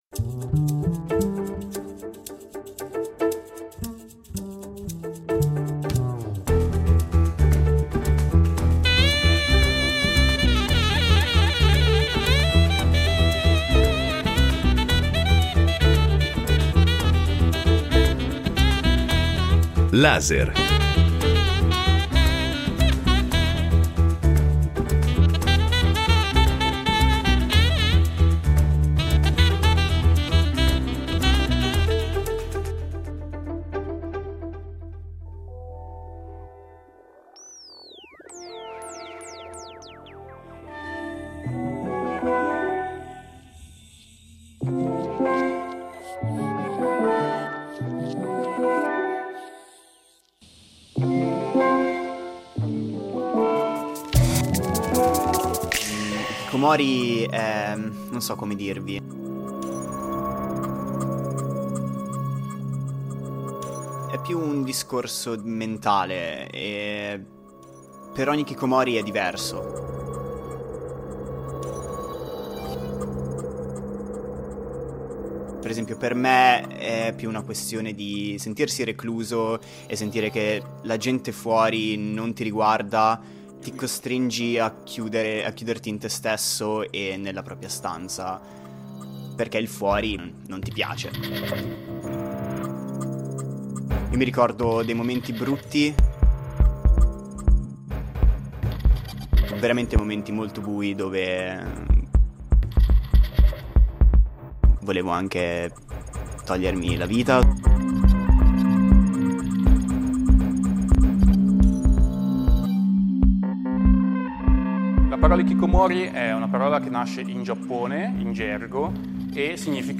Il documentario affronta questo mondo dall'interno, attraverso le voci di testimoni, esperti e frammenti dai diari di giovani che vivono in completo isolamento, per comprendere una realtà complessa, radicata nelle criticità del nostro tempo.